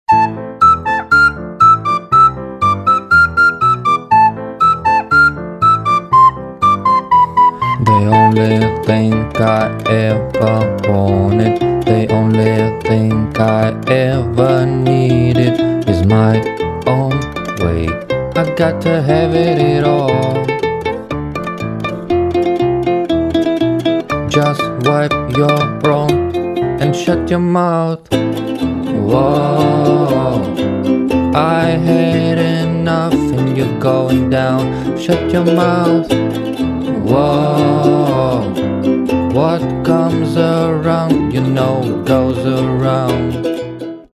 инструментальные , дудка
укулеле